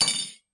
餐具的声音 " 小勺子6
Tag: 餐具